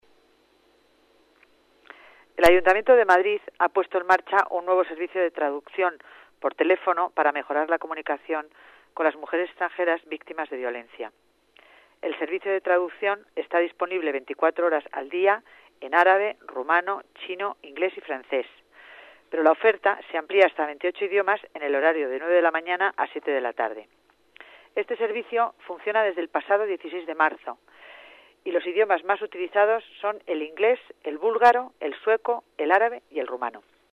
Nueva ventana:Concepción Dancausa, delegada de Familia, informa sobre idiomas en las denuncias de violencia de género